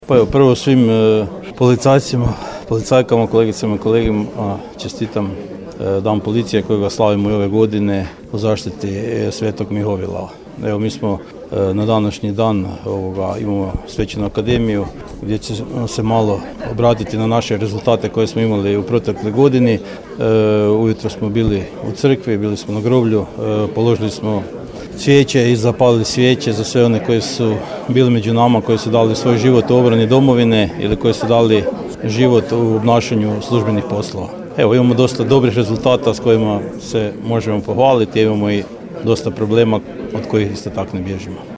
Policijska uprava međimurska svečanom akademijom u svom sjedištu u Čakovcu te odavanjem počasti poginulim policijskim službenicima i braniteljima obilježila je Dan policije koji se slavi 29. rujna, na blagdan svetog Mihovila.
Načelnik Ivan Sokač: